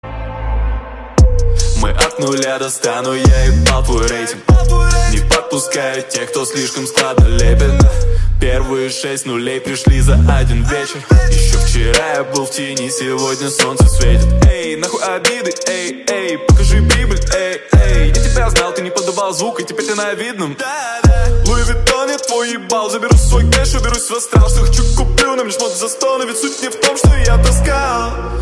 • Качество: 128, Stereo
громкие
русский рэп
басы
Четкий рэпчик